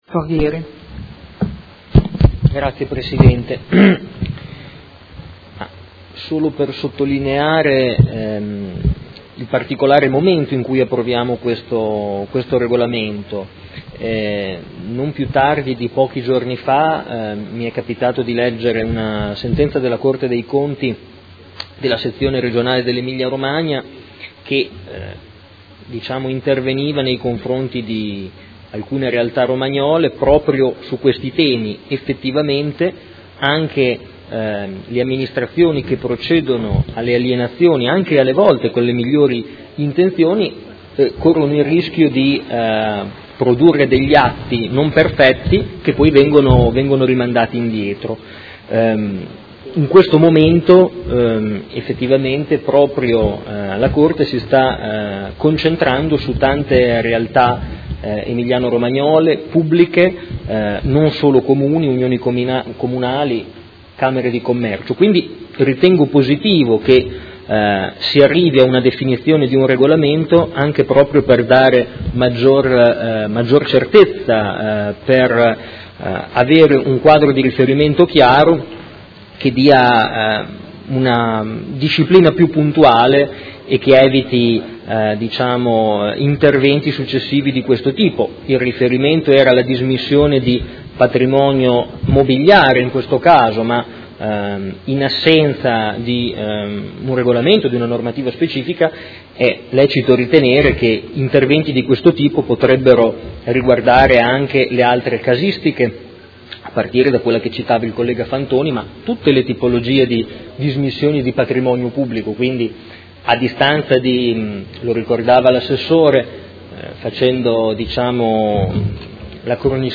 Seduta dell’8/11/2018. Dibattito su proposta di deliberazione: Regolamento per l’alienazione del patrimonio immobiliare – Approvazione